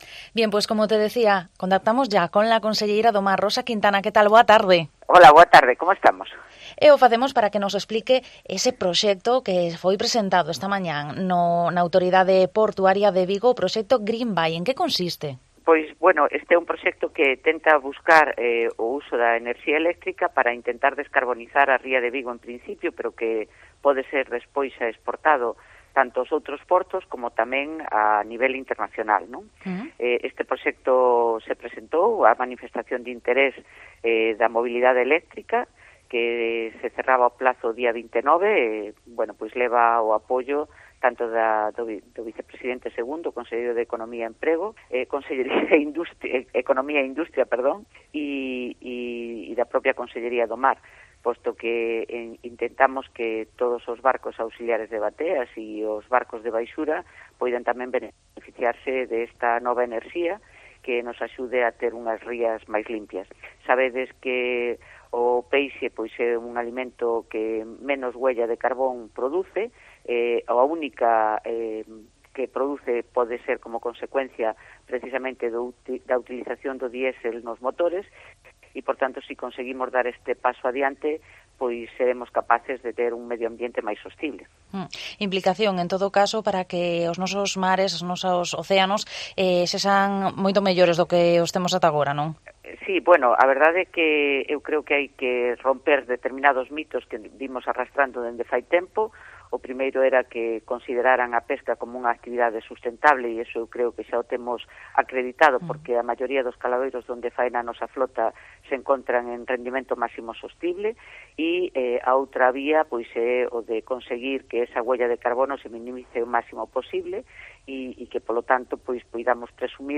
Rosa Quintana, conselleira do Mar, nos habla sobre el proyecto Green Bay